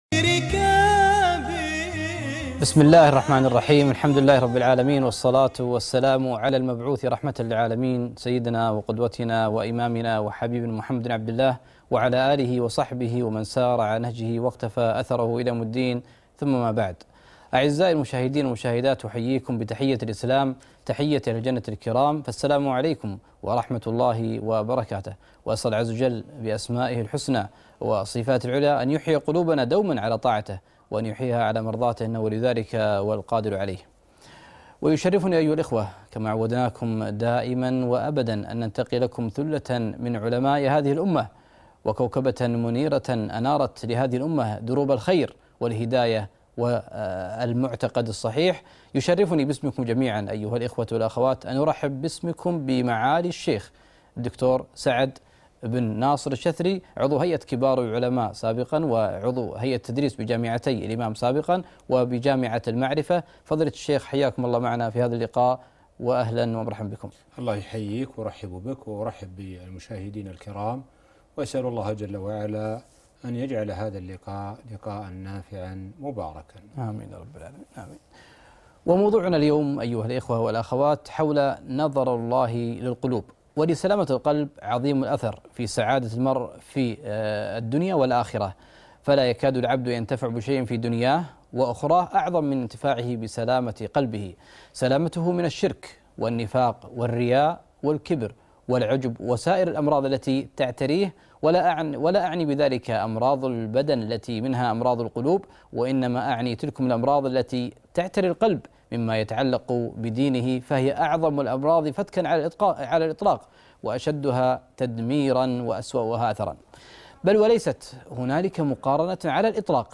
محاضرة - حياة القلوب (نظر الله للقلوب)